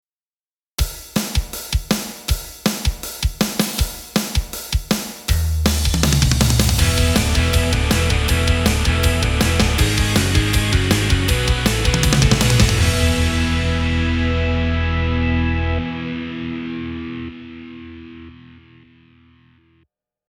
Think of it like a videogame music jingle or musical effect rather than actual full music.